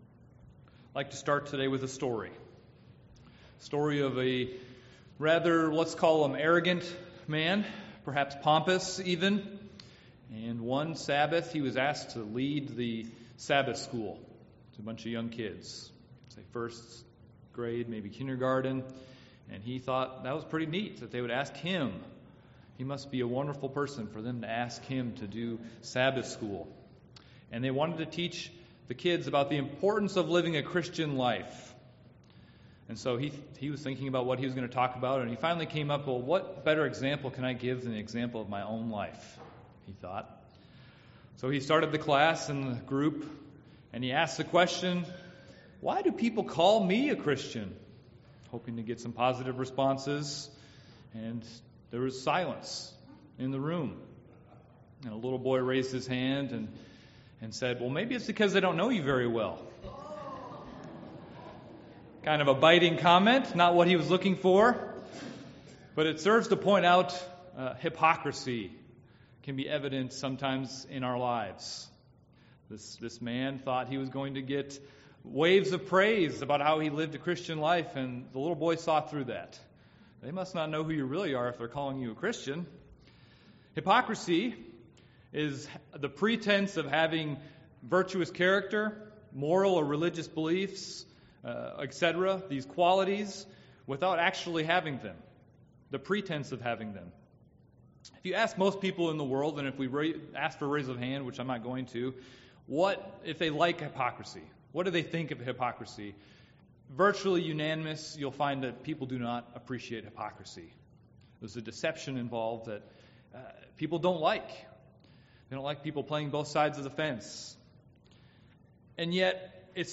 This sermon looks at 3 characteristics of different types of hypocrisy that we should avoid in our lives.